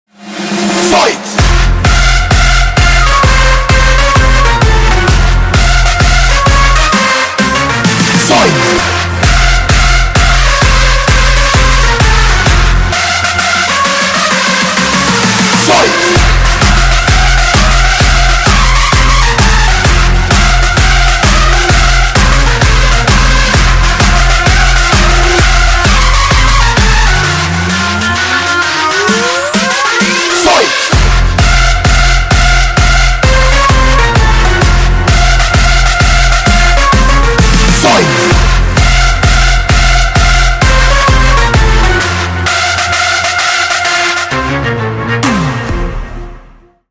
• Качество: 320, Stereo
громкие
жесткие
мощные
мотивирующие
качающие
энергичные
progressive house
злые
агрессивные